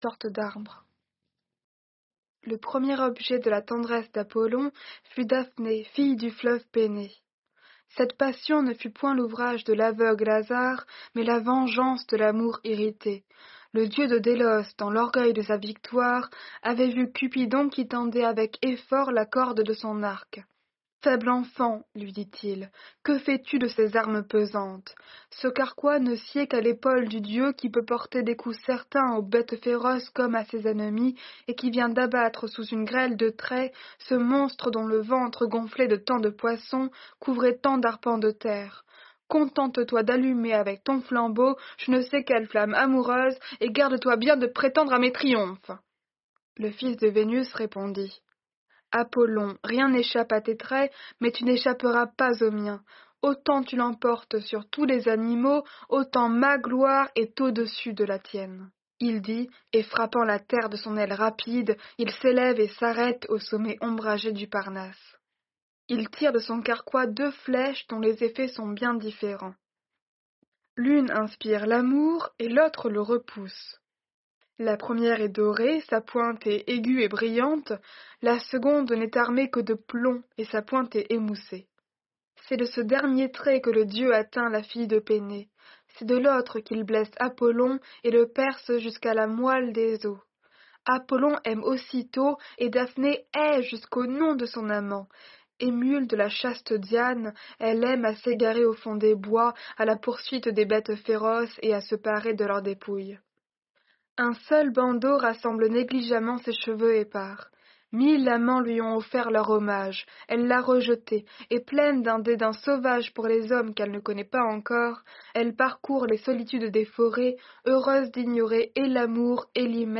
Lecture de la métamorphose de Daphné · GPC Groupe 1